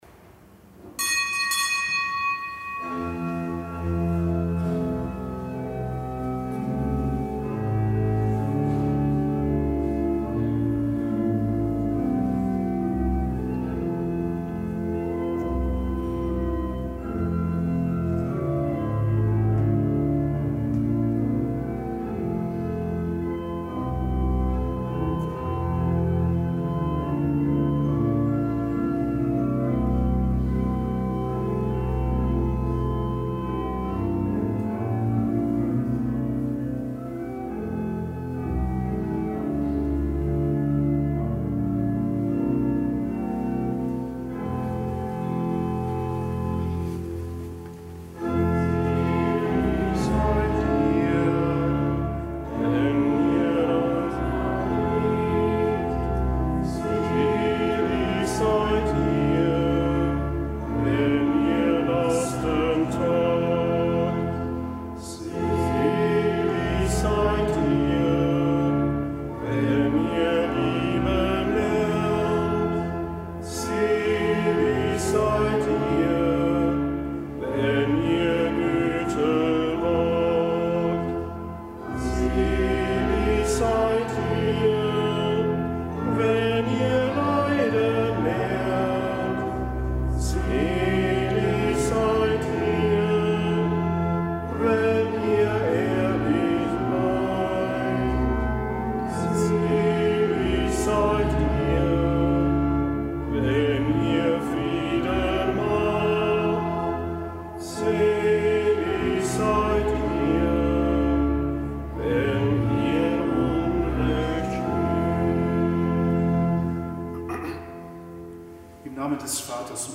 Kapitelsmesse aus dem Kölner Dom am Samstag der ersten Fastenwoche. Am nichtgebotenen Gedenktag des Heiligen Klemens Maria Hofbauer, einem römisch-katholischen Ordenpriester.